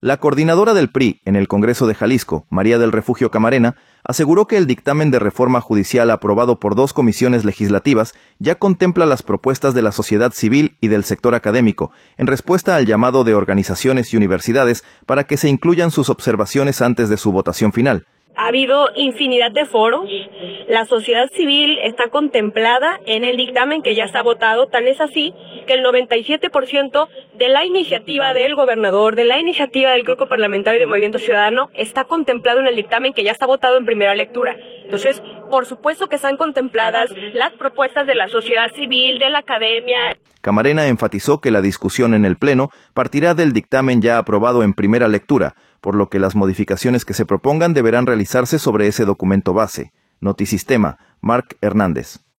Dictamen de reforma judicial cumple con peticiones, afirma diputada